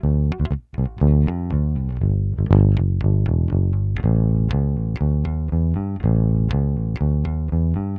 Index of /90_sSampleCDs/Best Service ProSamples vol.48 - Disco Fever [AKAI] 1CD/Partition D/BASS-FINGER